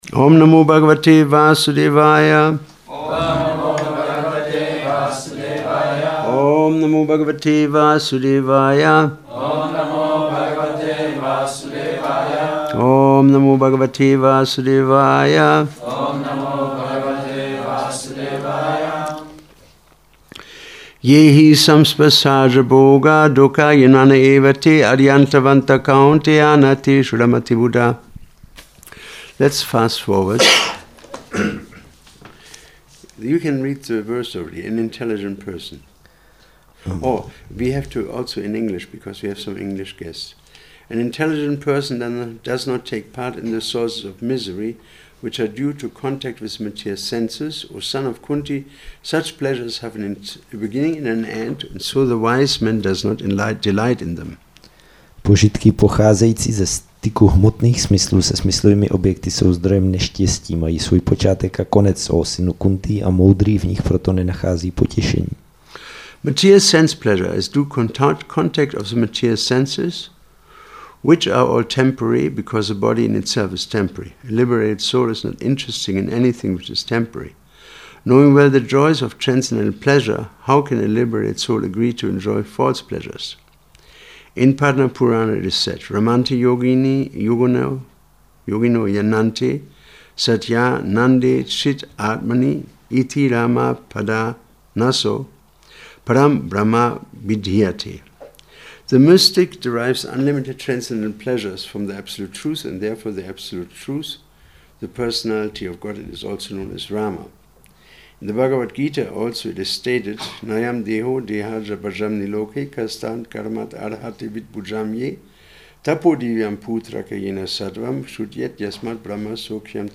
Přednáška BG-5.22 – Šrí Šrí Nitái Navadvípačandra mandir